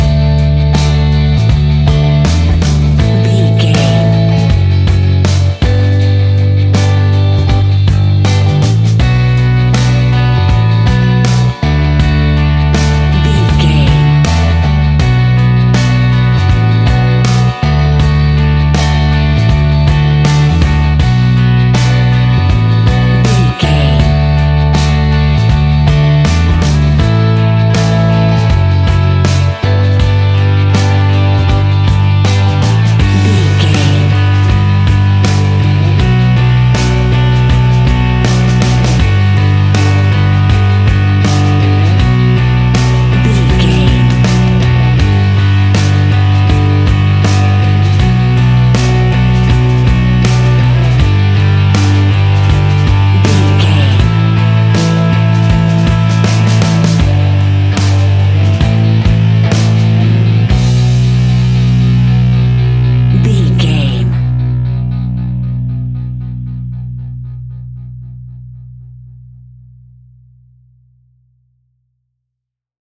Aeolian/Minor
hard rock
blues rock
instrumentals
rock guitars
Rock Bass
Rock Drums
distorted guitars
hammond organ